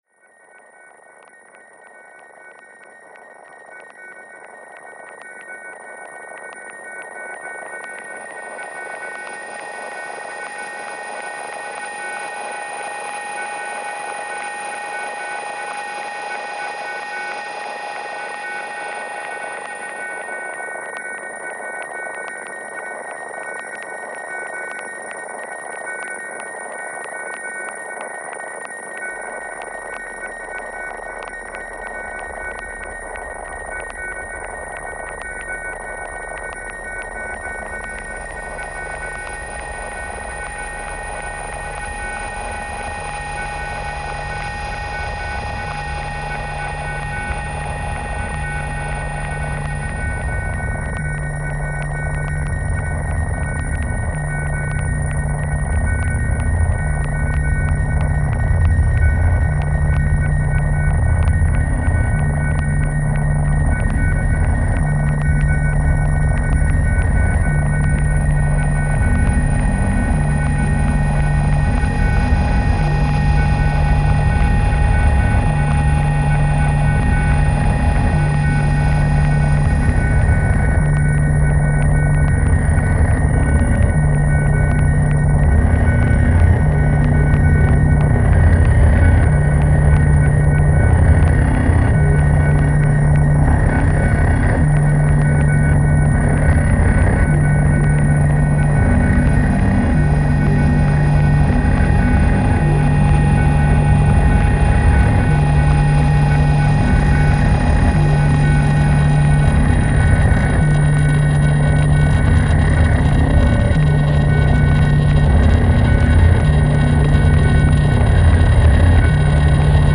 electronic duo
With their work in zero-bpm, music concréte and deep ambient
eerie and expansive soundscapes